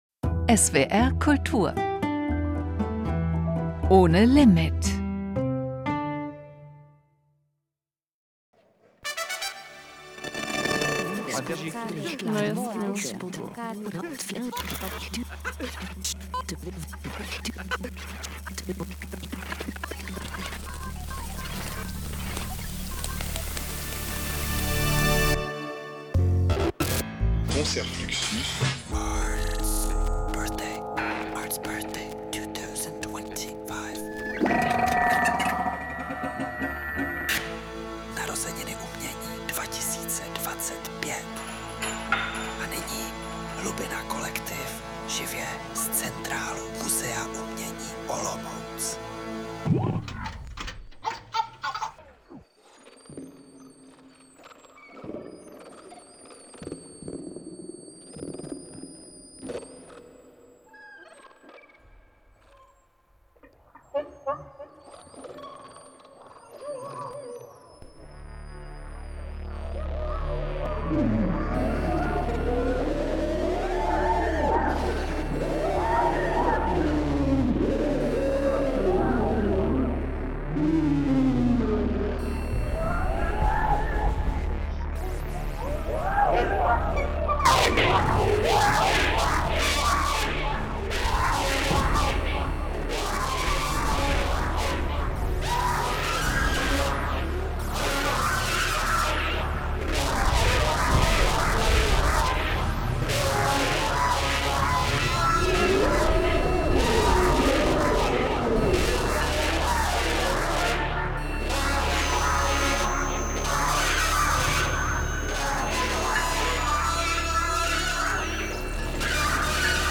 Ars Acustica ist ein Klanglabor, in dem Musik auf Literatur und Bildende Kunst trifft. Hier experimentieren Klangkünstler*innen frei mit Pop, Jazz, Geräuschen, Zitaten, Sprache und allem, was klingt.